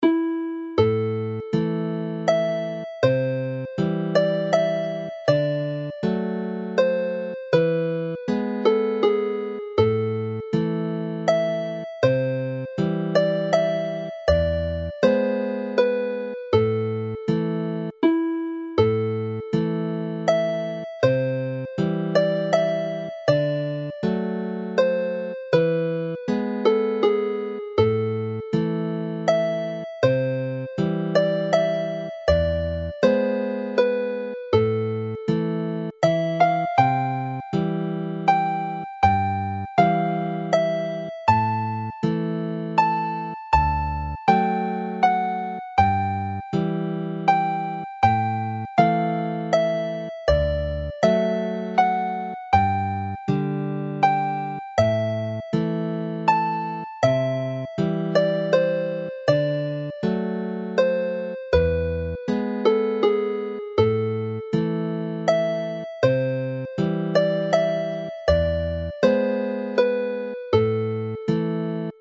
All three melodies are in the Welsh A minor which use a G# in the scale, giving an E major chord rather than the E minor generally found in Irish and Scottish tunes.
Melody and accompaniment